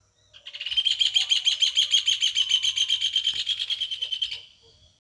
Rufous Hornero (Furnarius rufus)
Location or protected area: Concepción del Yaguareté Corá
Condition: Wild
Certainty: Observed, Recorded vocal